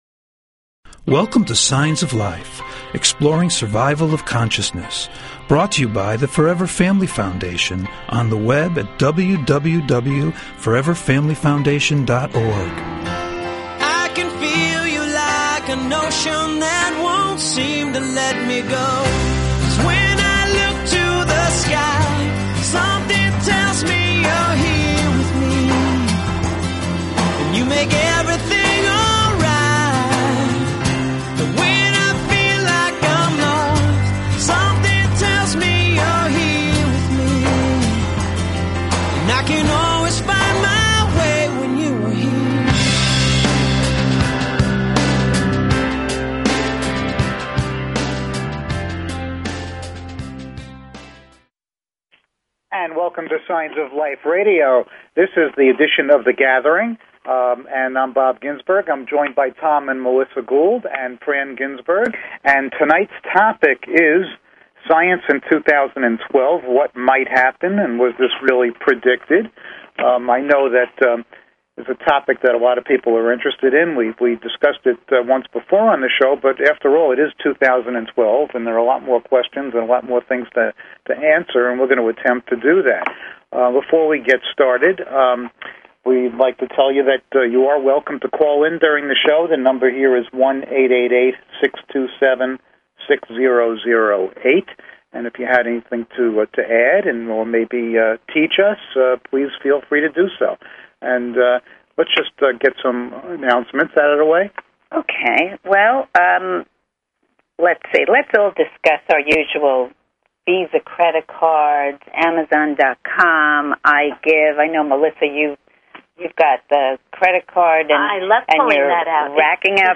SHORT DESCRIPTION - The Gathering discussion show - Topic: Science & 2012
Call In or just listen to top Scientists, Mediums, and Researchers discuss their personal work in the field and answer your most perplexing questions.